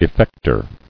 [ef·fec·tor]